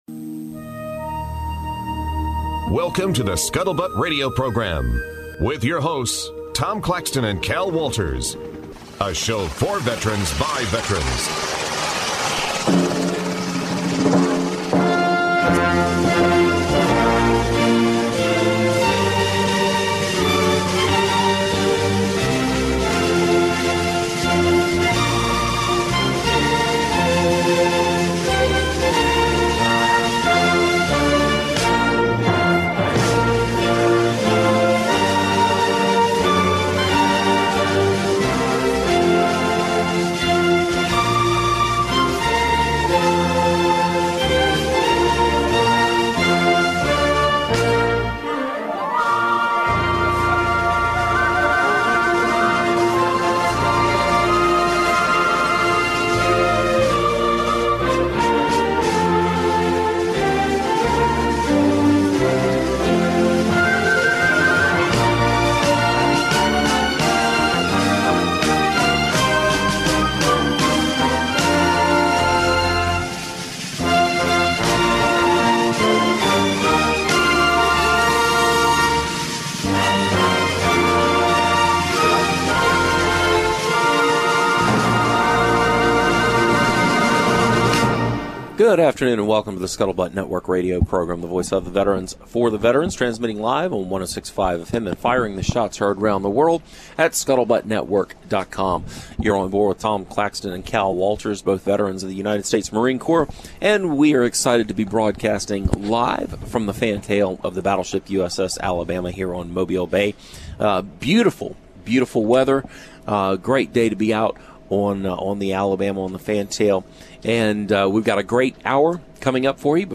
Today's episode originates onboard the USS Alabama at Memorial Park.